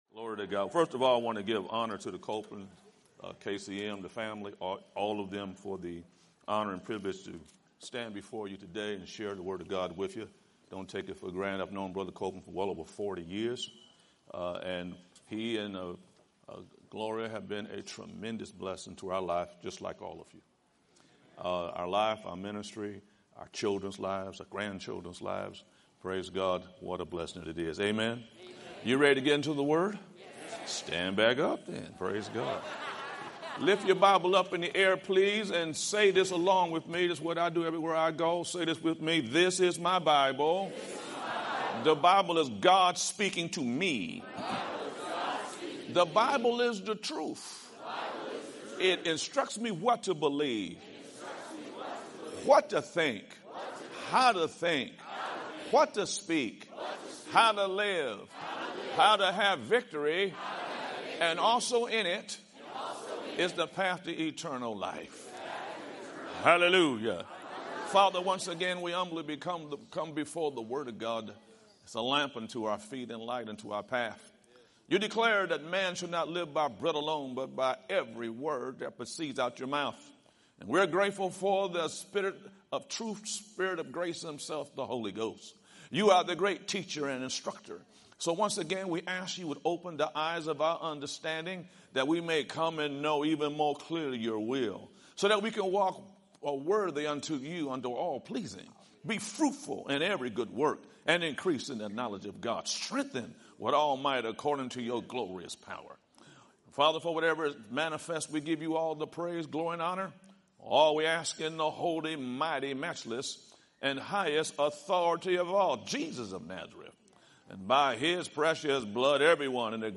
Branson Victory Campaign